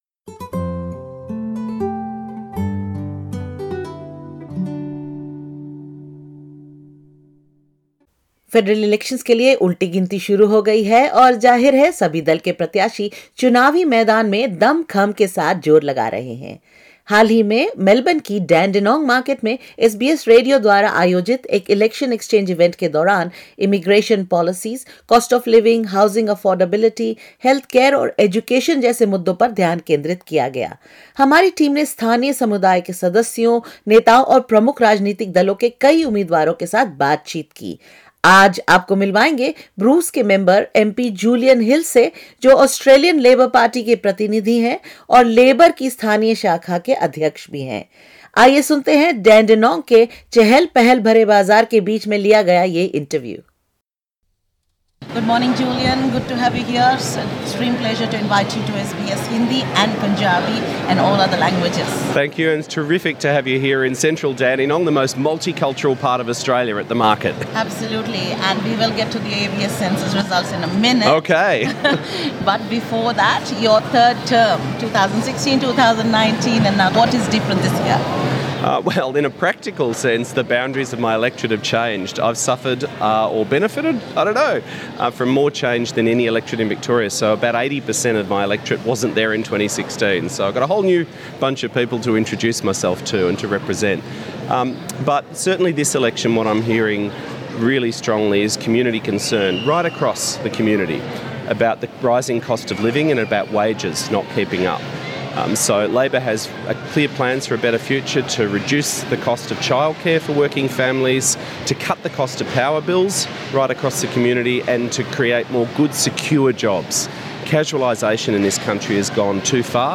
Labor member for Bruce Julian Hill at the SBS Election Exchange held in Dandenong Market, Victoria.
Mr Hill made the comments in an interview with SBS Hindi at the recent SBS Election Exchange held at the Dandenong Market in Victoria.